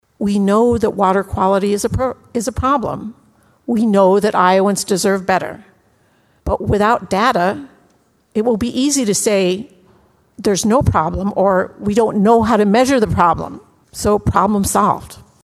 Senator Janice Weiner, a Democrat from Iowa City, says the bill cuts funding for the Iowa Nutrient Research Center at Iowa State University and likely ends its collaboration with the Iowa Flood Center at the University of Iowa. Weiner says it’s University of Iowa staff who’ve been measuring whether water quality projects are working.